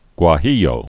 (gwä-hēyō, gwə-)